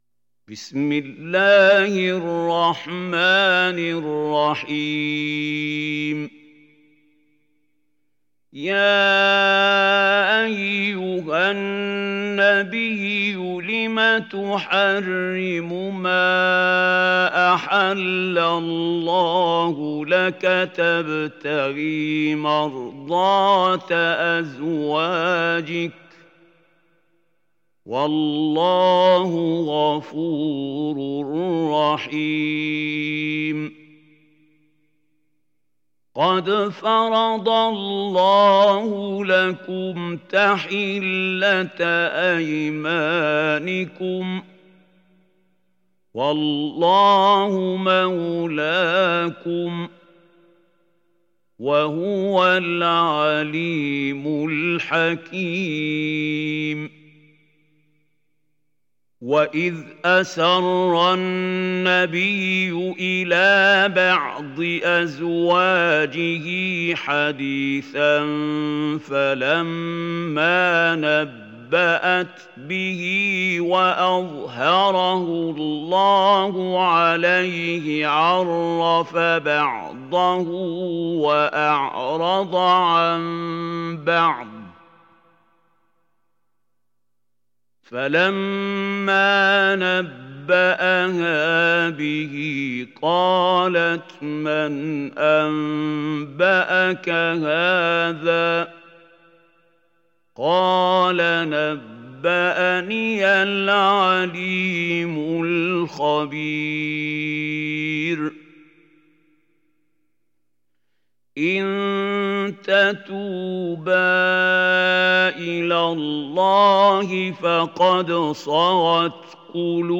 دانلود سوره التحريم mp3 محمود خليل الحصري روایت حفص از عاصم, قرآن را دانلود کنید و گوش کن mp3 ، لینک مستقیم کامل